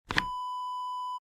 Small drawer open sound effect .wav #2
Description: The sound of a small wooden drawer being opened
Properties: 48.000 kHz 16-bit Stereo
A beep sound is embedded in the audio preview file but it is not present in the high resolution downloadable wav file.
Keywords: wooden, small, tiny, drawer, dresser, pull, pulling, open, opening
drawer-small-open-preview-2.mp3